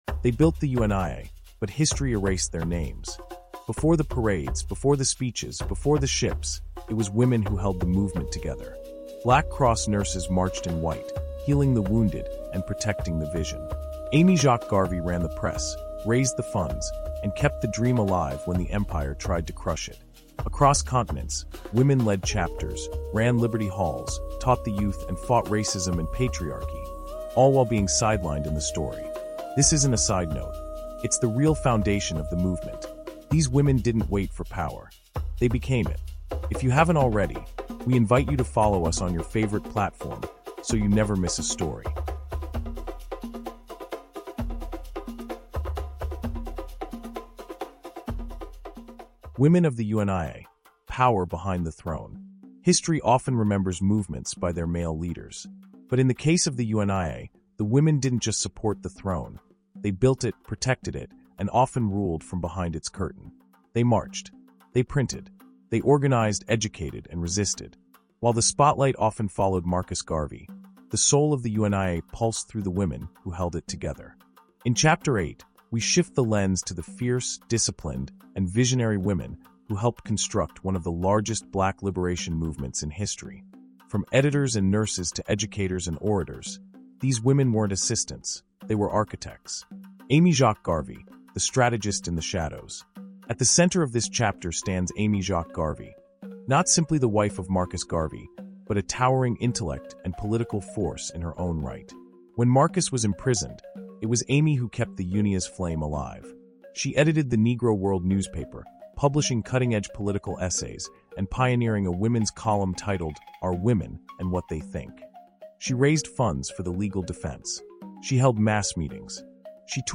UNIA: Women of the UNIA | Audiobook